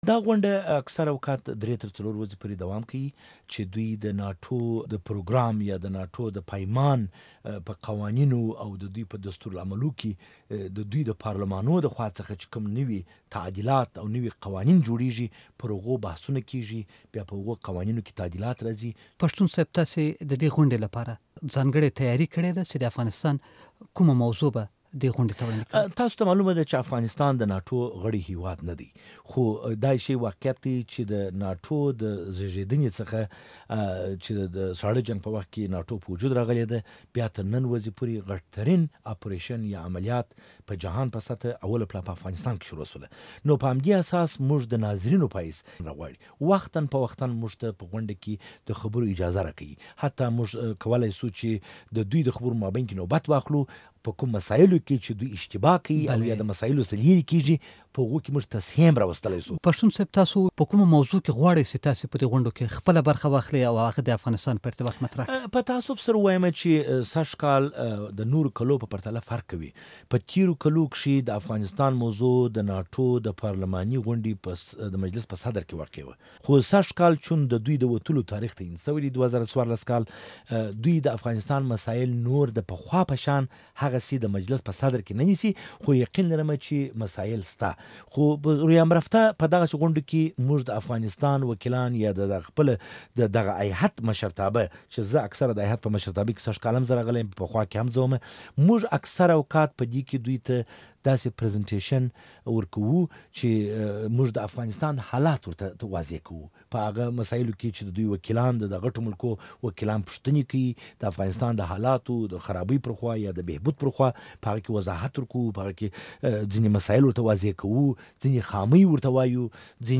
له خالد پښتون سره مرکه